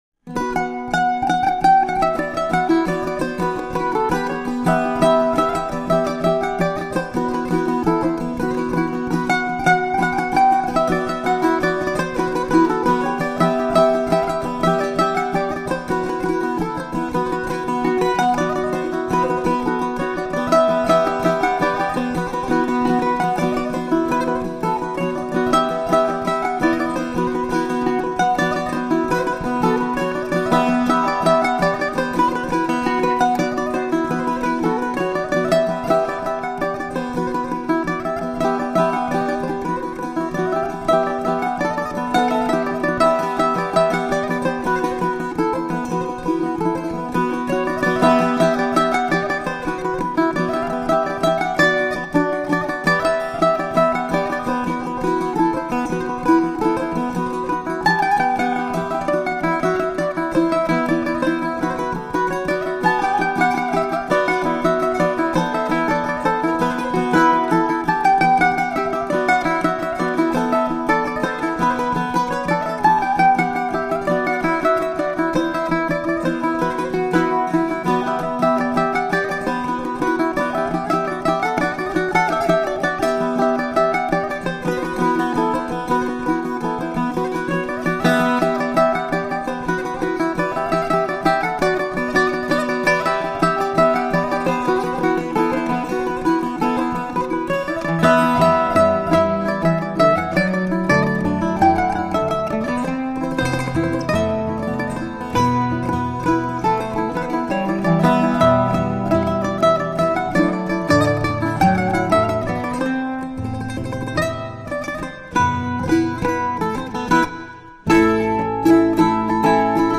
Recorded in Berkeley, California, 2002
Composition and Bulgarian tamburas
Acoustic bass guitar